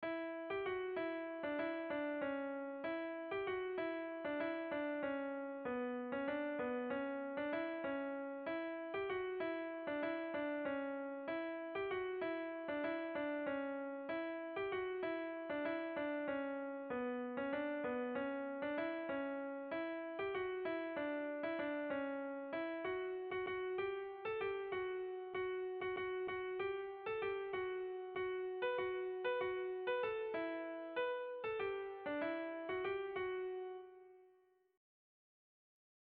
Bertso melodies - View details   To know more about this section
Irrizkoa
AB